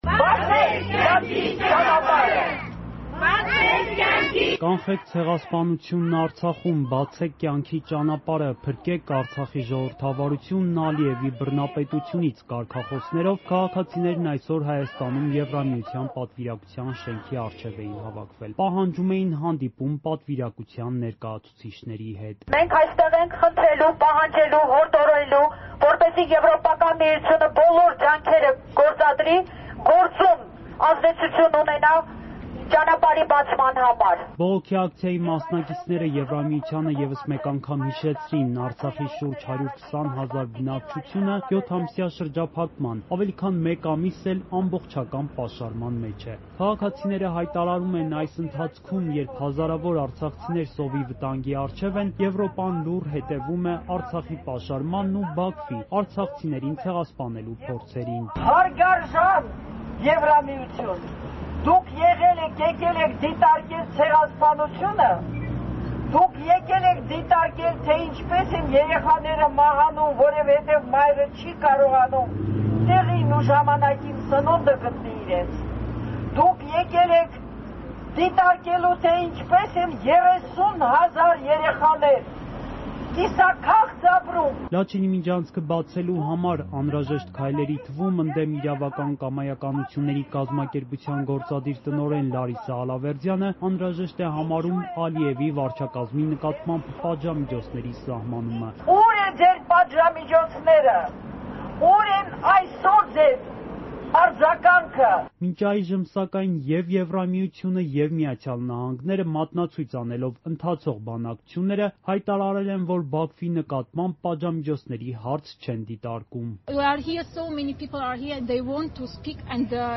«Կանխեք ցեղասպանությունն Արցախում» կարգախոսով ակցիա՝ ԵՄ պատվիրակության շենքի առջև
Ռեպորտաժներ